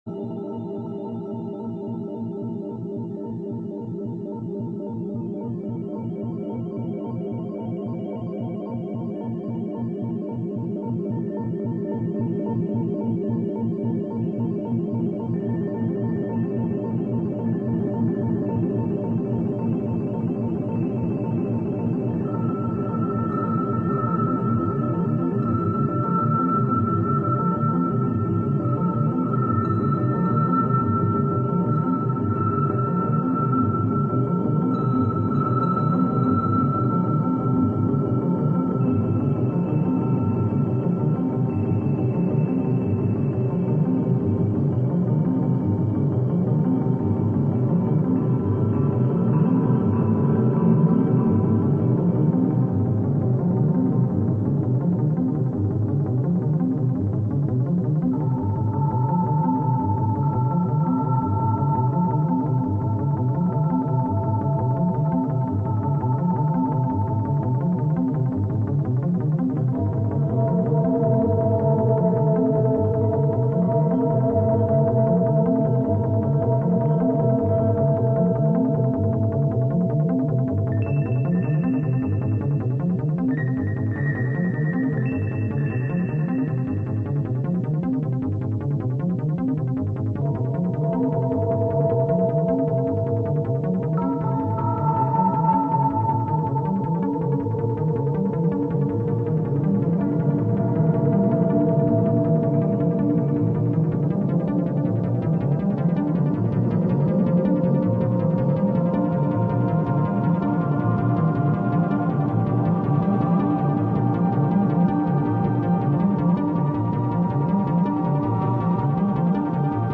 Live weekly sessions at Studio Cyclops